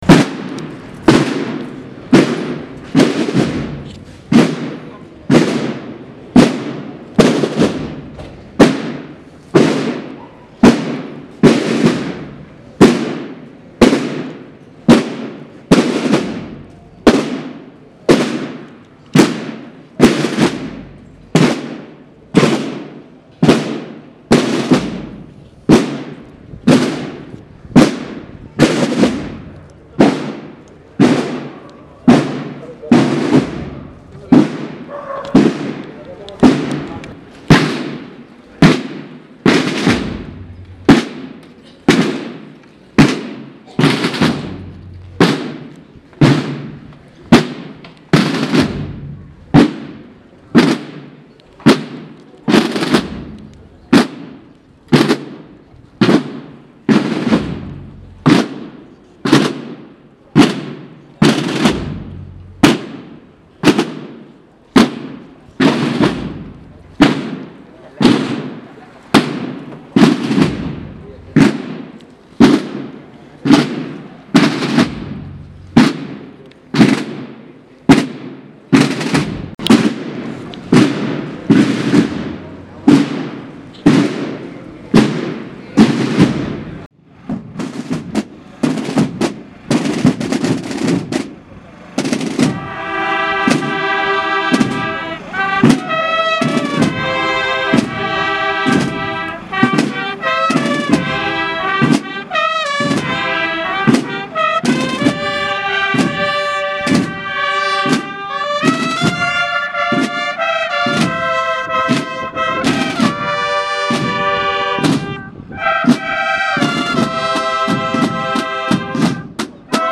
Vía Crucis. Hdad. de Jesús en el Calvario - 2016
Semana Santa de Totana